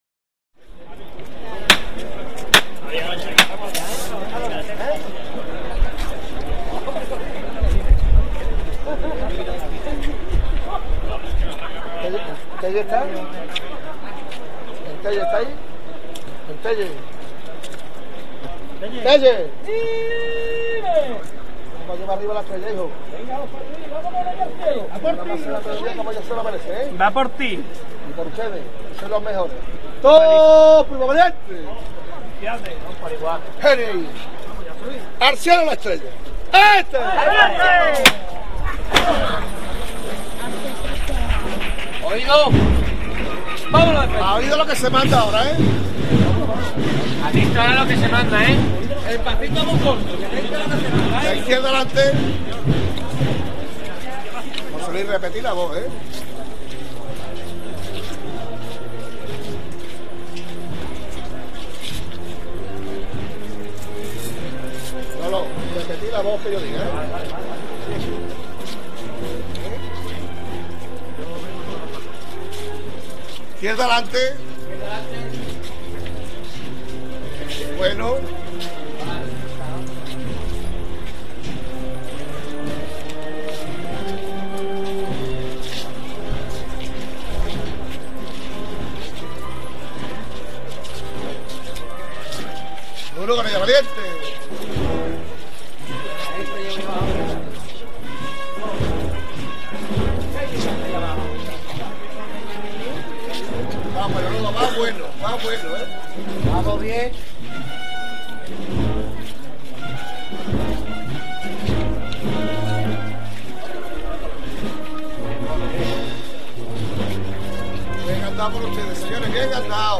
Jerez B.M. Municipal de Guillena Jerez, Domingo de Ramos 2007 Grabación Tiniebla 01. Amarguras (calle Tornería)